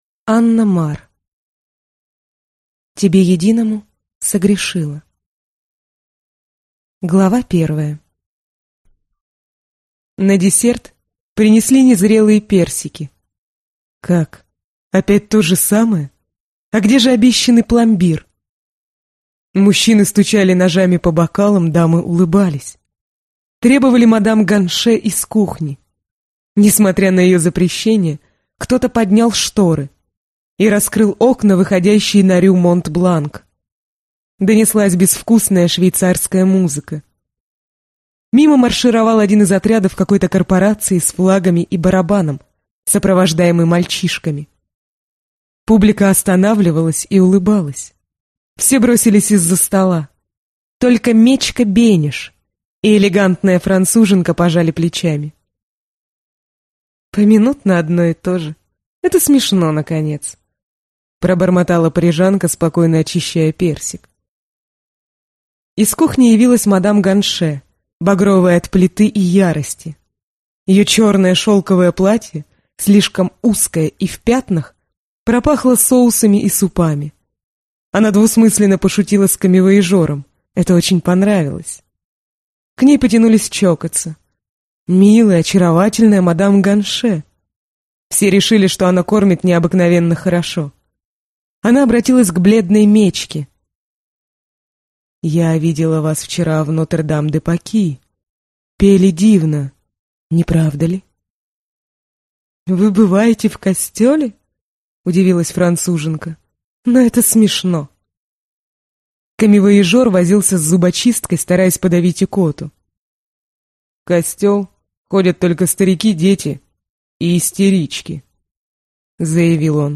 Аудиокнига Тебе единому согрешила | Библиотека аудиокниг